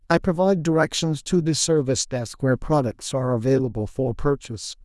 TTS_audio / PromptTTS++ /sample1 /Template2 /Condition /Customer /Emotion /angry /displeased.wav
displeased.wav